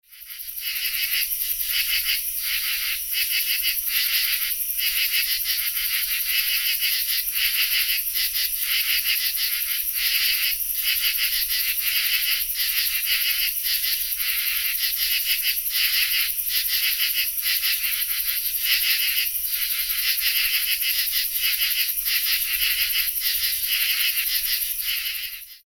Common True Katydid sings from low perch1 ELC 8-21-13
Common True Katydid
Common-True-Katydid-chorus-in-one-large-oak-Burton-Wetlands.mp3